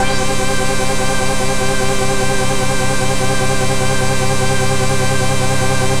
Index of /90_sSampleCDs/Trance_Explosion_Vol1/Instrument Multi-samples/Scary Synth
G3_scary_synth.wav